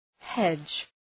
{hedʒ}